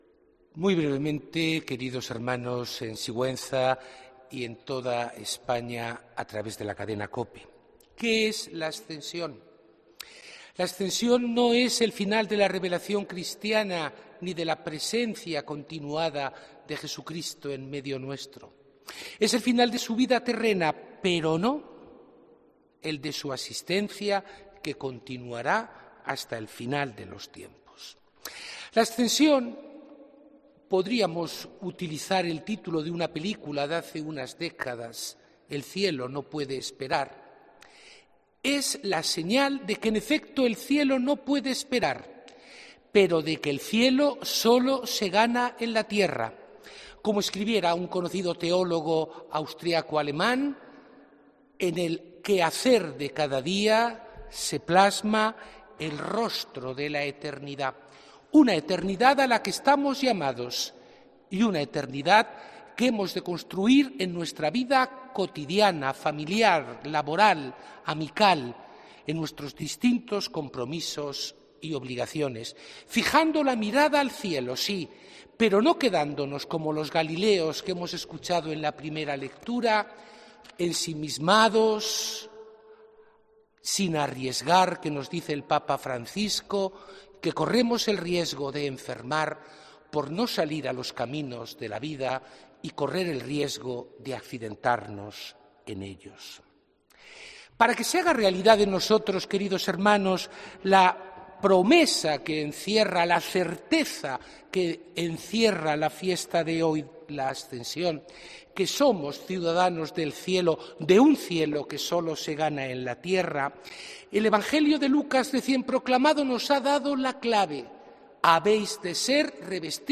HOMILÍA 2 JUNIO 2019